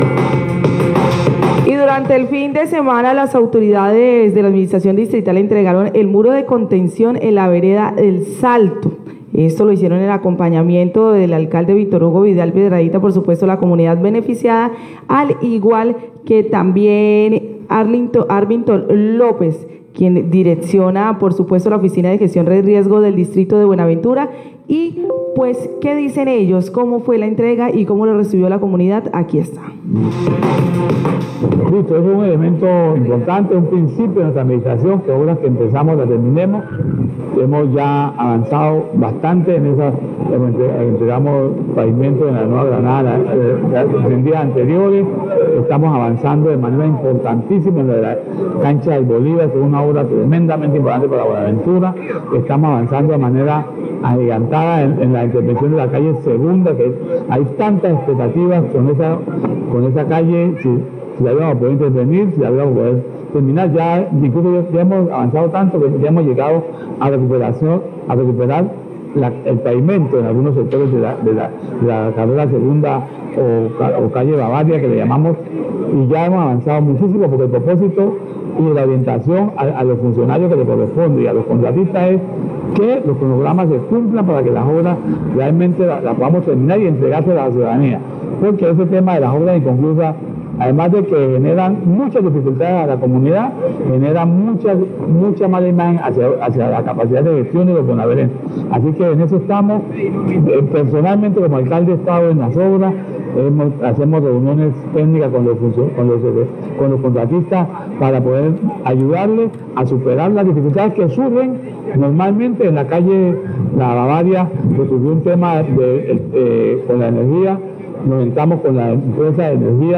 Radio
El alcalde  Victor Hugo Vidal, presenta un balance de las obras que esta realizando el distrito.